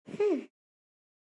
惊悚片鸟类 " 鸟类1惊悚片1O
描述：另一种鸟类（对不起，我不是鸟类学家；一些'吃苹果的人'），在波兰录制。这是第一个八度的下移。虽然在参考八度听起来很正常，但接下来八度的下移会发现真正可怕的声音和纹理，就像哥斯拉或一些恐龙。
标签： 效果 现场记录 自然 纹理 惊悚
声道立体声